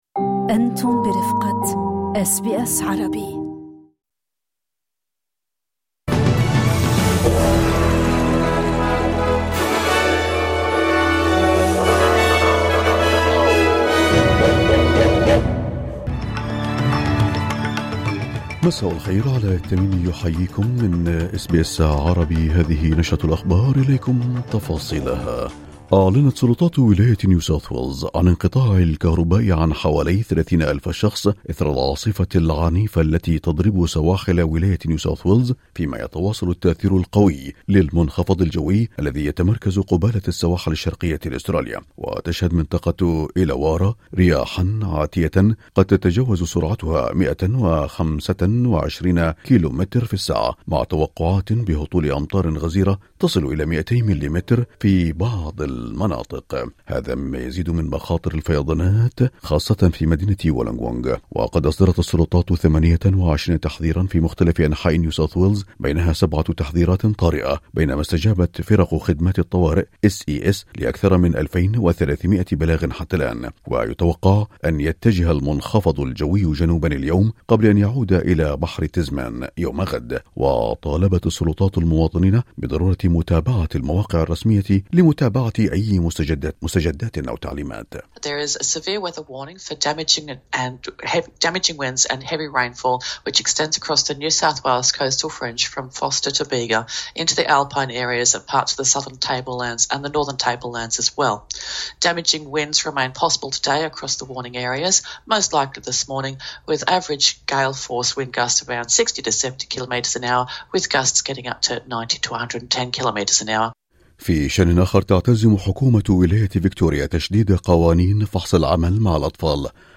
نشرة أخبار الظهيرة 2/7/2025